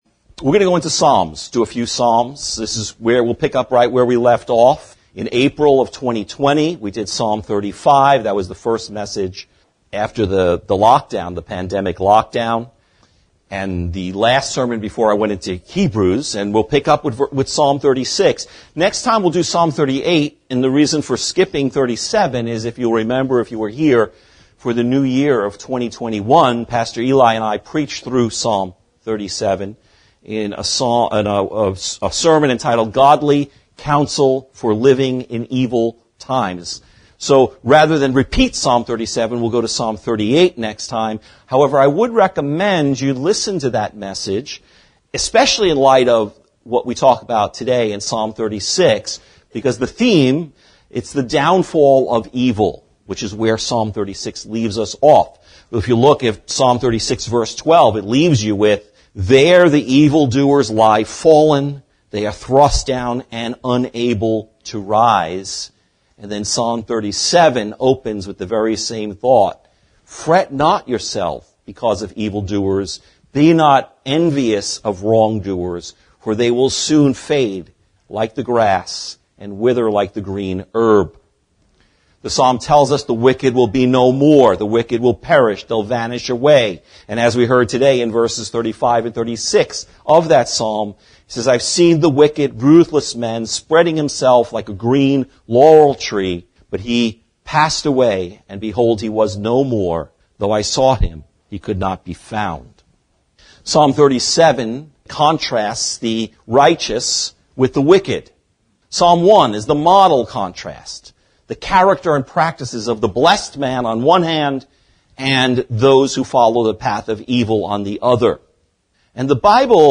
An Oracle of Infinite Contrast | SermonAudio Broadcaster is Live View the Live Stream Share this sermon Disabled by adblocker Copy URL Copied!